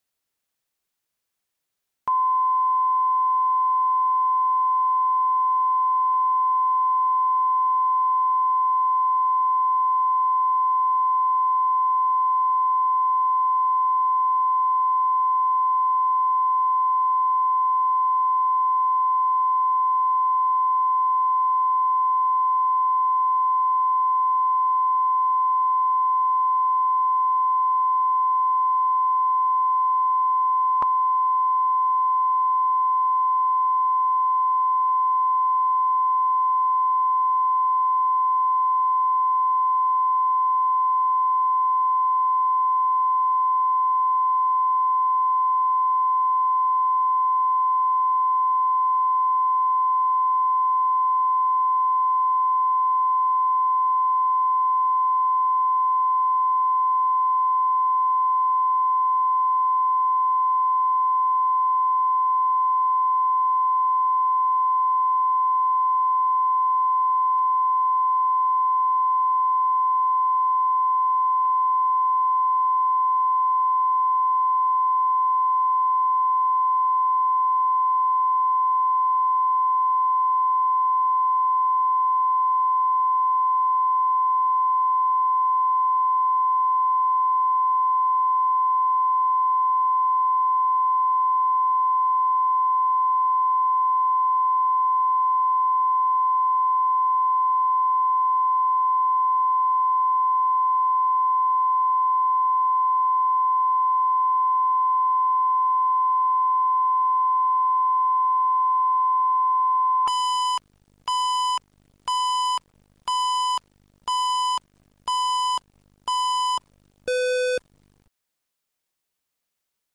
Аудиокнига Нескупой рыцарь | Библиотека аудиокниг